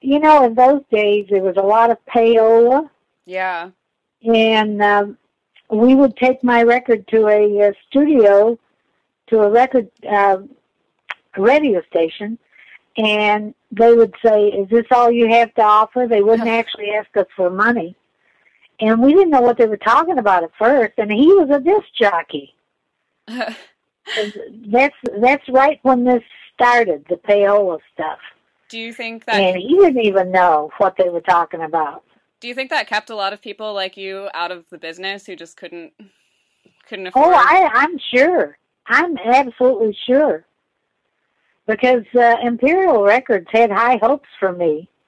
Interview Highlights